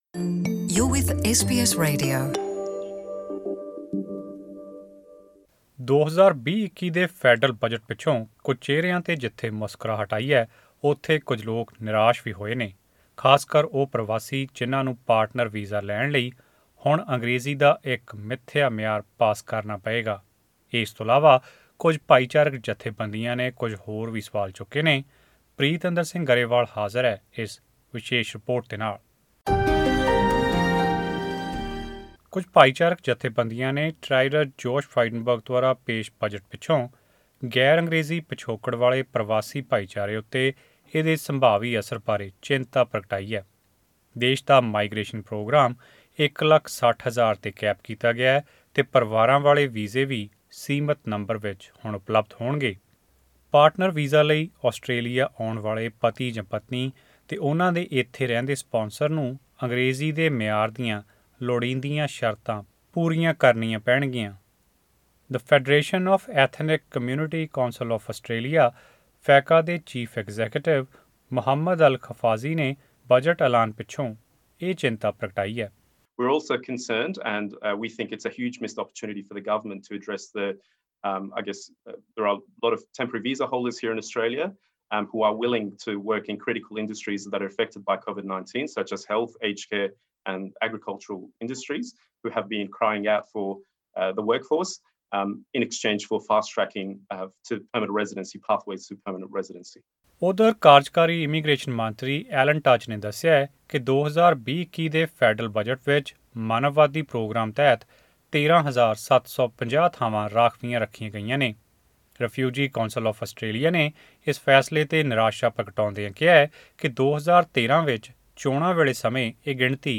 2020 ਦੇ ਫੈਡਰਲ ਬਜਟ ਪਿੱਛੋਂ ਕੁਝ ਚਿਹਰਿਆਂ ਤੇ ਜਿੱਥੇ ਮੁਸਕਰਾਹਟ ਆਈ ਹੈ ਉੱਥੇ ਕੁਝ ਲੋਕ ਨਿਰਾਸ਼ ਵੀ ਹੋਏ ਹਨ, ਖਾਸ ਕਰ ਉਹ ਪ੍ਰਵਾਸੀ ਜਿਨ੍ਹਾਂ ਨੂੰ ਪਾਰਟਨਰ ਵੀਜ਼ਾ ਲੈਣ ਲਈ ਹੁਣ ਅੰਗਰੇਜ਼ੀ ਦਾ ਇੱਕ ਮਿੱਥਿਆ ਮਿਆਰ ਪਾਸ ਕਰਨਾ ਪਏਗਾ। ਇਸ ਤੋਂ ਇਲਾਵਾ ਕੁਝ ਭਾਈਚਾਰਕ ਜਥੇਬੰਦੀਆਂ ਨੇ ਕੁਝ ਹੋਰ ਵੀ ਸਵਾਲ ਚੁੱਕੇ ਨੇ, ਆਓ ਜਾਣੀਏ ਇਸ ਵਿਸ਼ੇਸ਼ ਆਡੀਓ ਰਿਪੋਰਟ ਵਿੱਚ.....